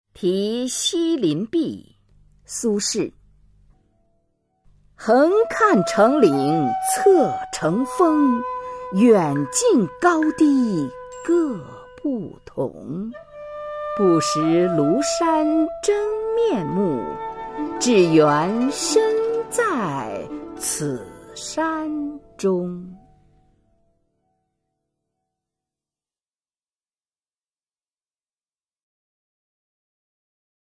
[宋代诗词诵读]苏轼-题西林壁 宋词朗诵